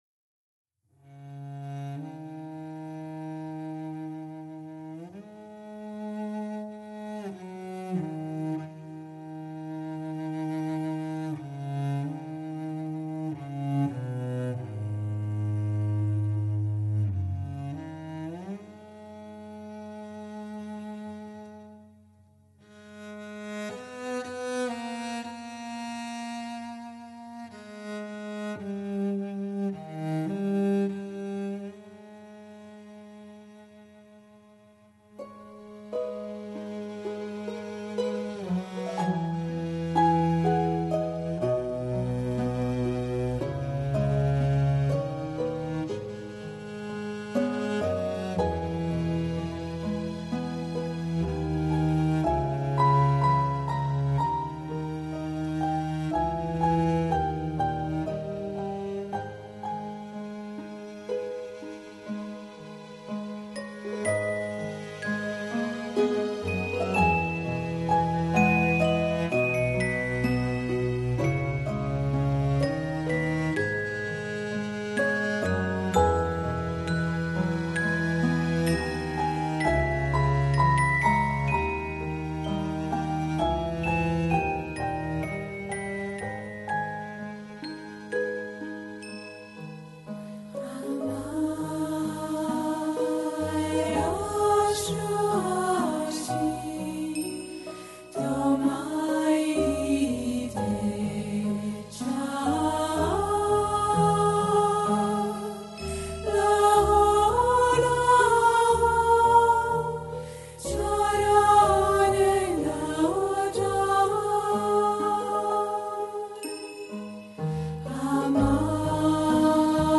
World, Mantras, Meditative Источник